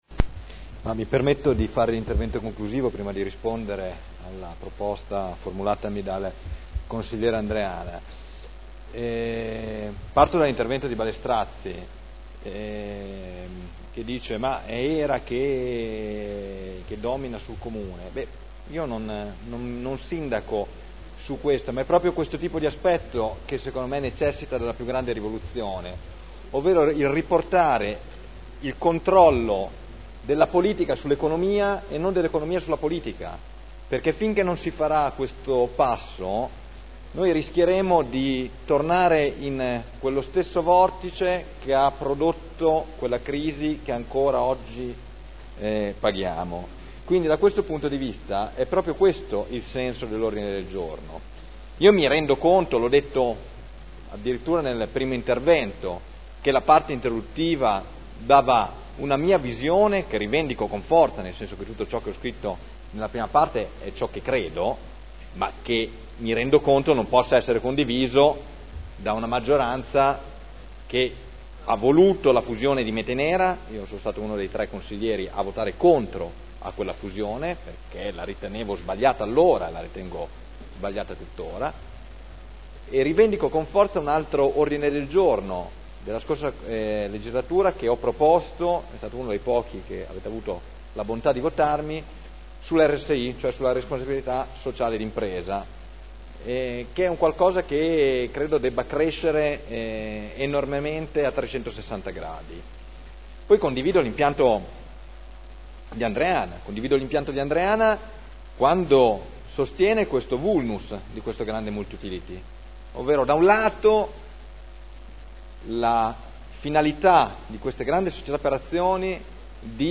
Seduta del 06/06/2011. Mozione presentata dai consiglieri Barcaiuolo, Taddei, Galli, Pellacani, Morandi, Bellei, Vecchi, Santoro (PdL) avente per oggetto: "Trasparenza HERA" Dibattito